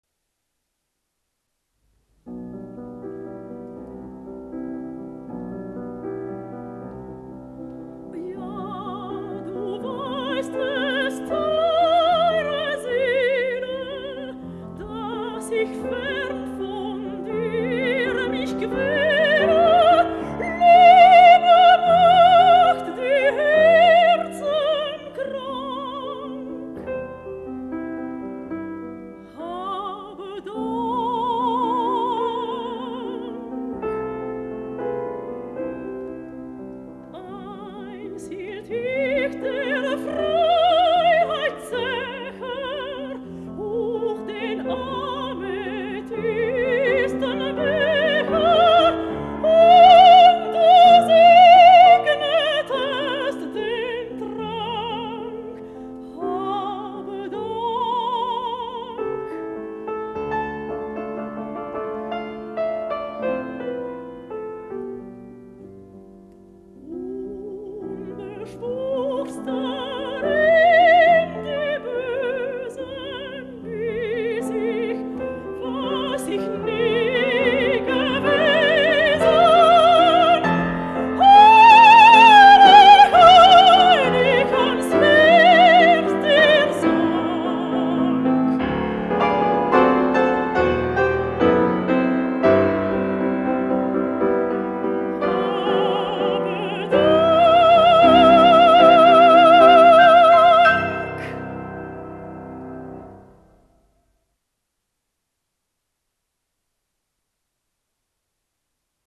soprano Composer